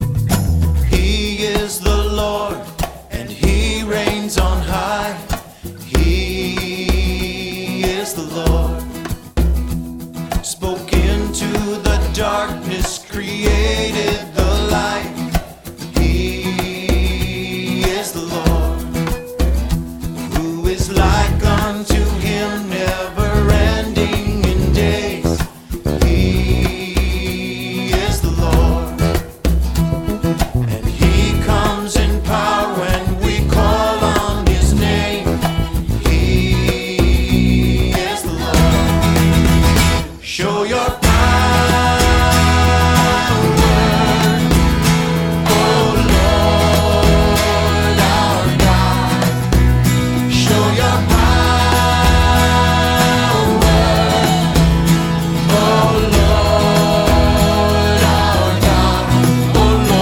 50 of the most popular modern worship songs
• Sachgebiet: Praise & Worship